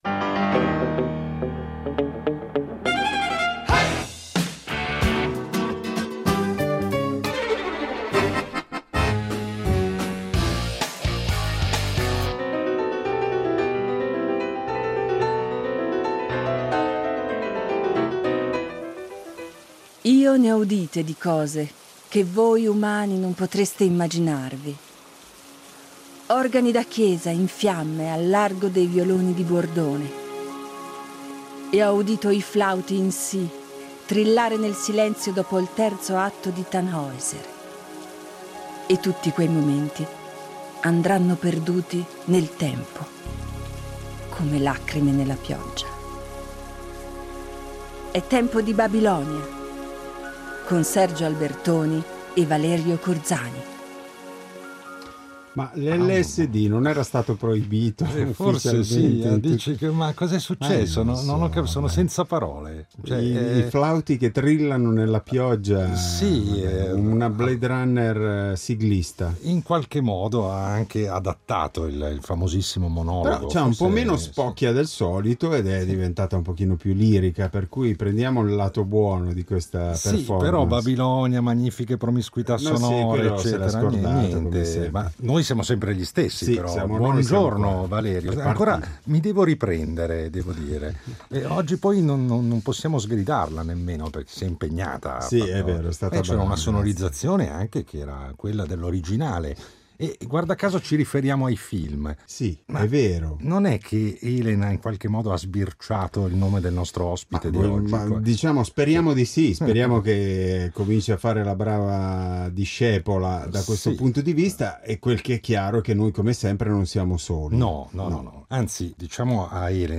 Il nostro ospite di oggi è notissimo anche al grande pubblico, in particolare, per la colonna sonora del capolavoro di Dario Argento “Profondo rosso” .
È impossibile riassumere qui la sua lunga e prolifica carriera, ma l’anno scorso la Tsunami Edizioni ha pubblicato la sua biografia, “ Il ragazzo d’argento ”, che parte dalla sua adolescenza vissuta in Brasile e attraversa 50 anni di musica: una straordinaria miniera di spunti, quindi, per le nostre chiacchierate tra un ascolto sbalestrante e l’altro.